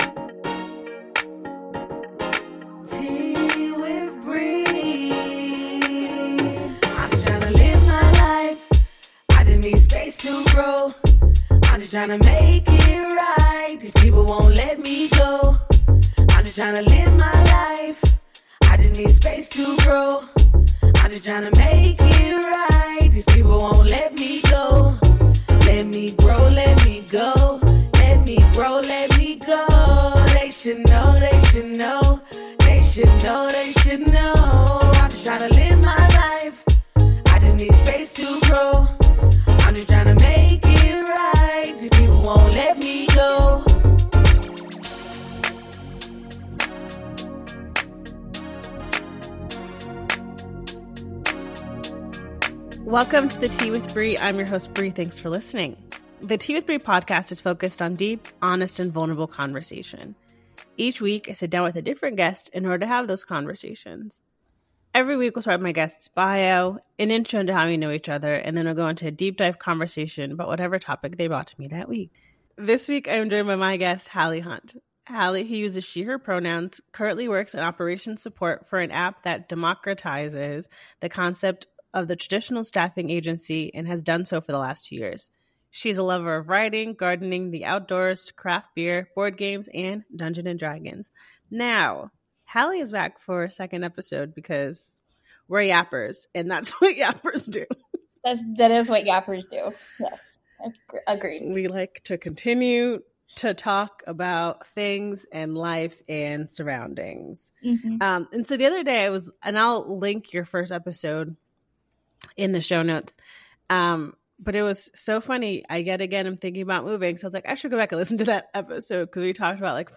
----- This podcast was recorded via Riverside FM.